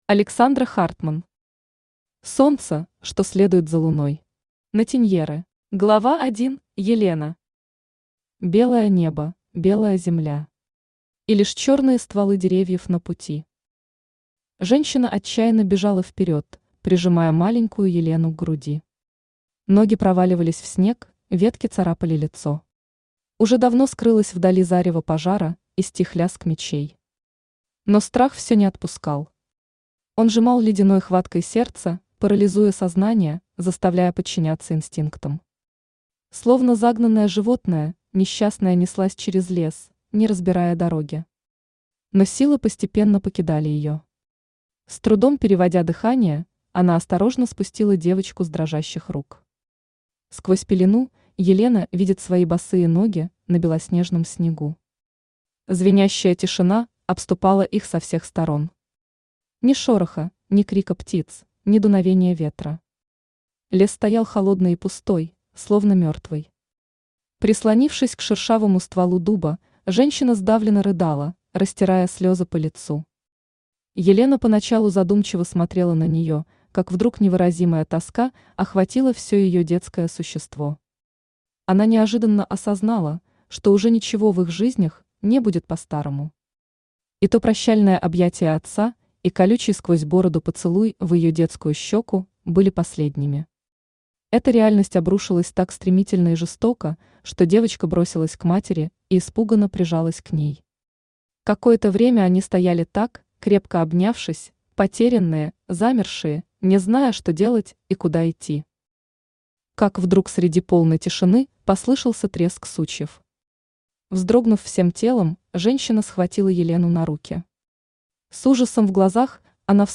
Аудиокнига Солнце, что следует за Луной. Наттеньеры | Библиотека аудиокниг
Наттеньеры Автор Александра Хартманн Читает аудиокнигу Авточтец ЛитРес.